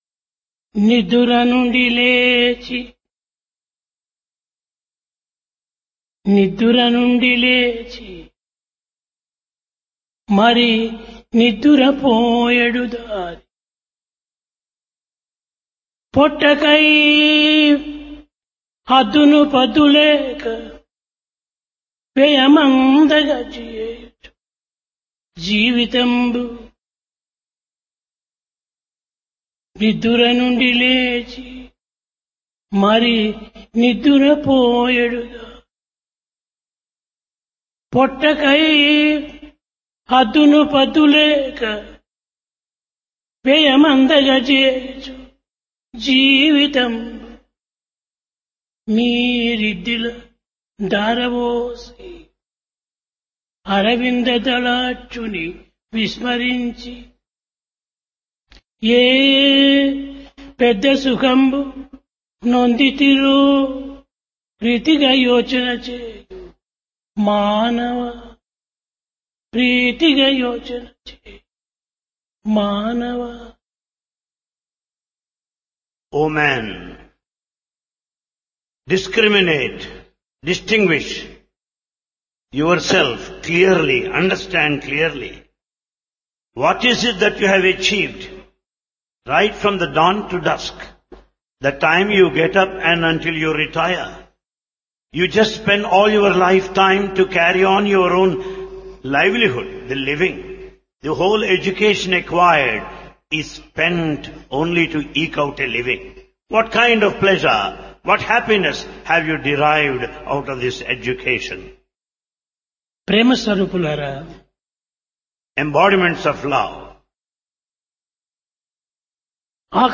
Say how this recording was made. Place Prasanthi Nilayam Occasion Onam